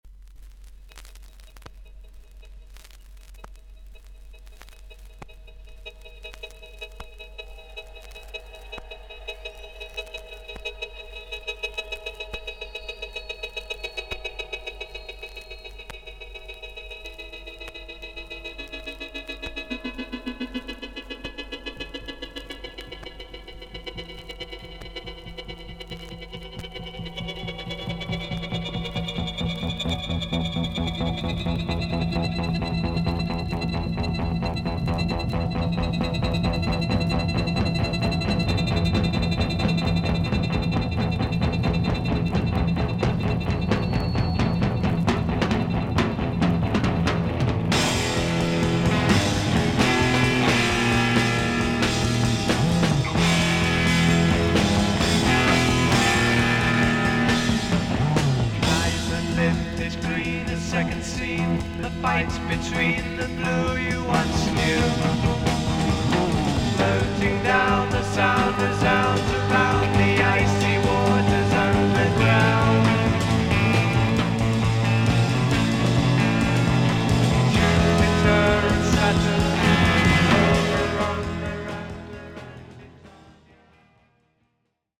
1面最初に小キズがあり、30秒ほど軽い周回ノイズあり。